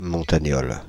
Ääntäminen
Synonyymit montagnard Ääntäminen France (Île-de-France): IPA: /mɔ̃.ta.ɲɔl/ Haettu sana löytyi näillä lähdekielillä: ranska Käännöksiä ei löytynyt valitulle kohdekielelle.